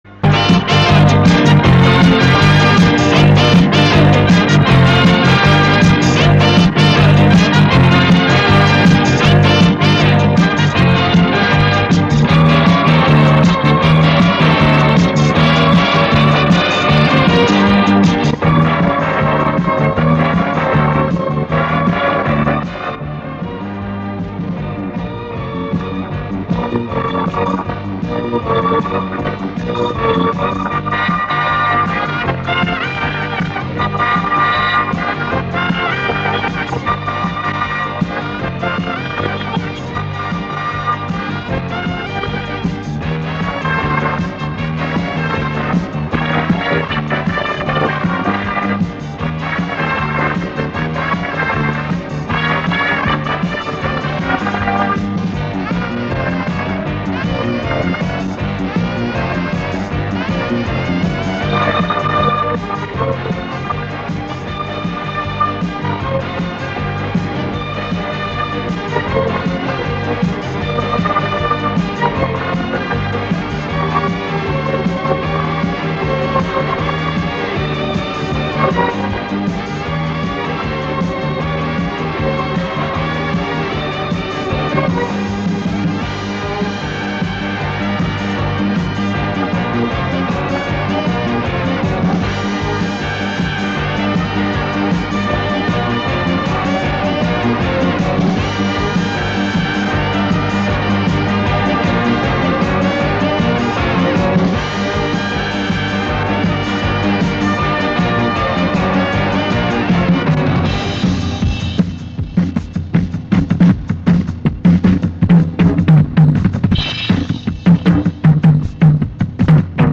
Прошу экспертов по инструментальной музыке опознать два муз. трека. Записано с телевизора в середине 80х годов.